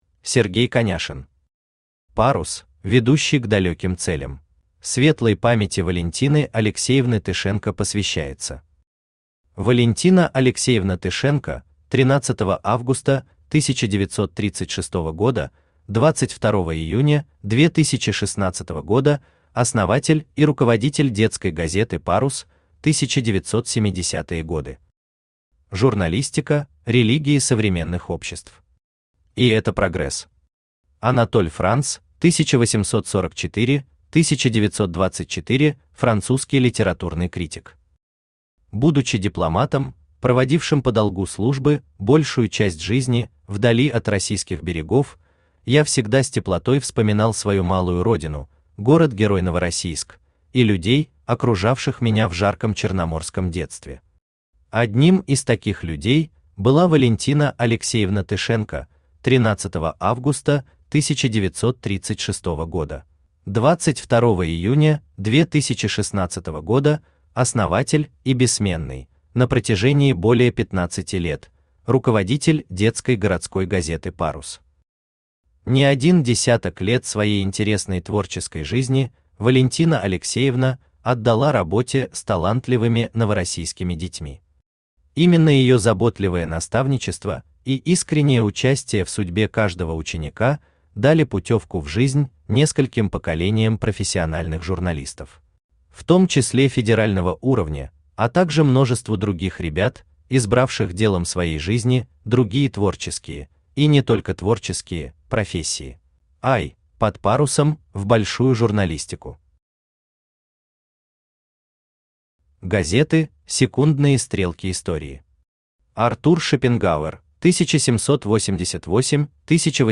Aудиокнига «Парус», ведущий к далеким целям Автор Сергей Сергеевич Коняшин Читает аудиокнигу Авточтец ЛитРес.